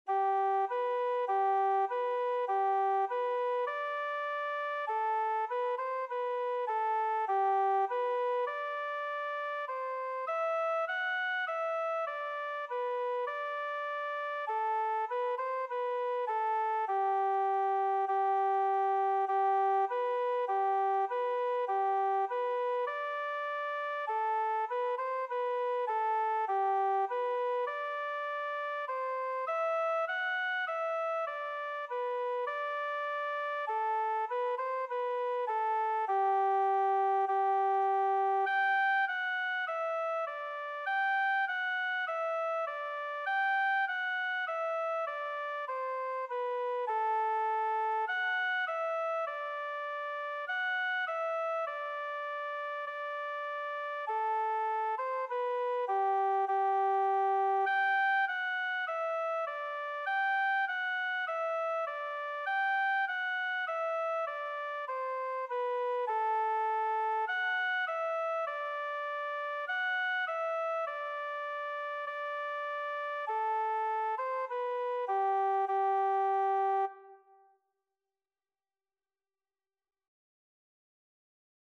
mp3 1 row melodeon in C pdf
melod-Winster_Galop.mp3